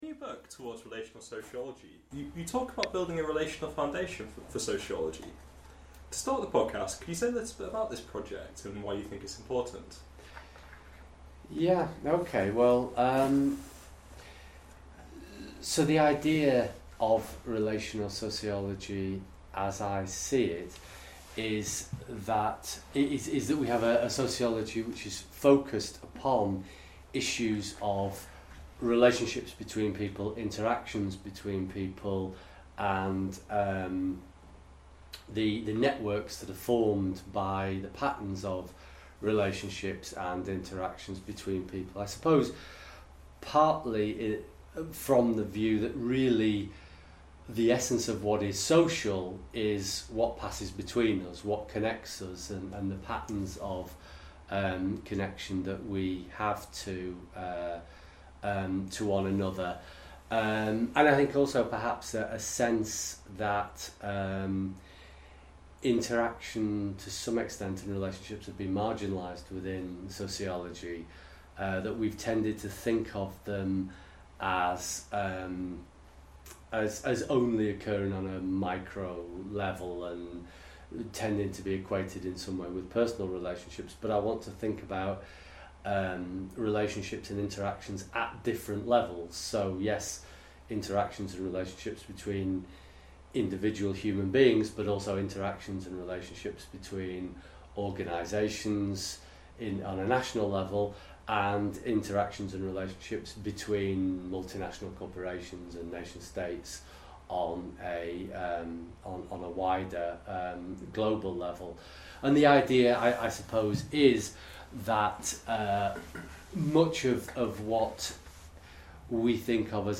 The interview covers relational sociology, interdisciplinary approaches to social theory, the future of social theory and the contested status of quantitative methods.